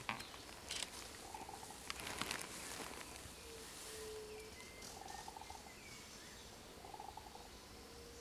Tapicurú (Mesembrinibis cayennensis)
Nombre en inglés: Green Ibis
Fase de la vida: Adulto
Localidad o área protegida: Reserva Privada y Ecolodge Surucuá
Condición: Silvestre
Certeza: Vocalización Grabada